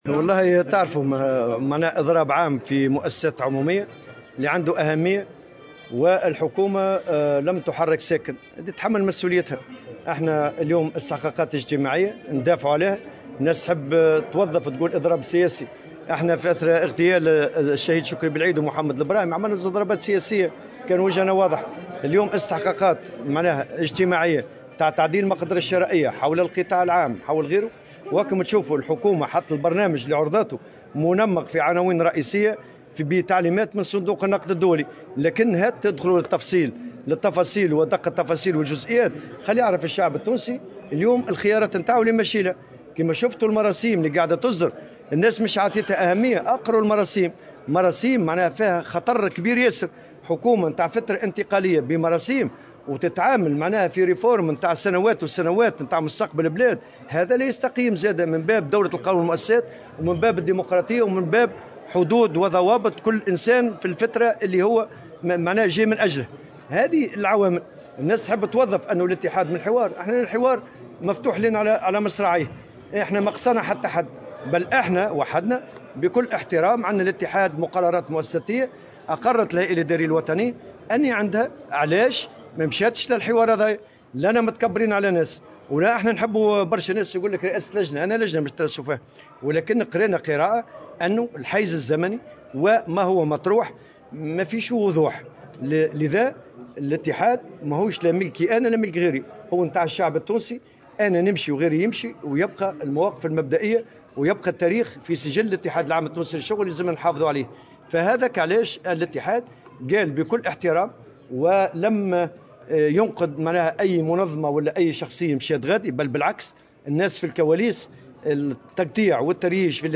وصف أمين عام اتحاد الشغل نور الدين الطبوبي في افتتاح المؤتمر الجهوي للاتحاد بتونس، اليوم السبت، المراسيم الأخيرة بالخطيرة، كونها صادرة عن حكومة مؤقتة تقوم بإصلاحات تتطلب سنوات لإجرائها، وهو ما اعتبره أمرا غير مقبول في دولة المؤسسات، بحسب تعبيره.